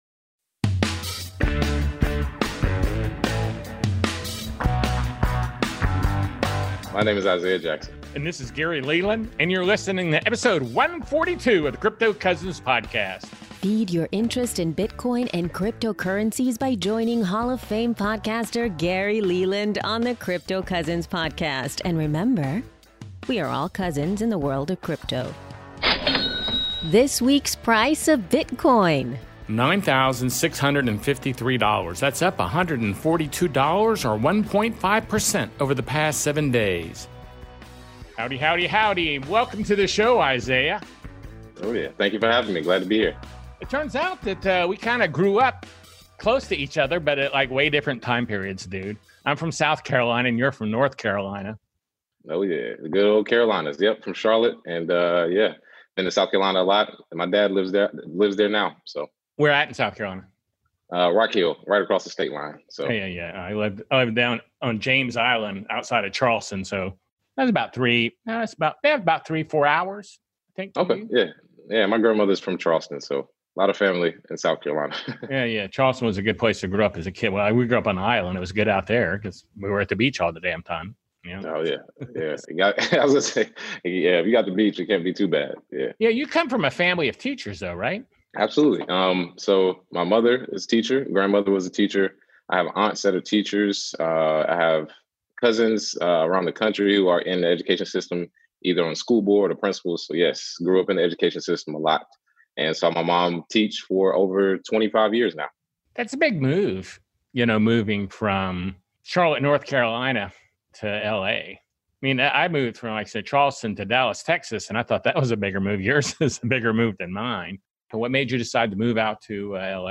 A great conversation